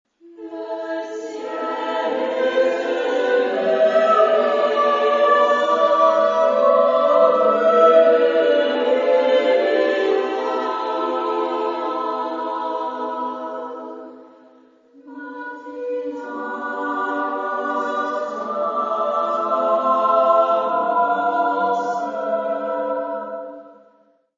Genre-Style-Forme : Profane ; Poème ; contemporain
Tonalité : mi (centré autour de)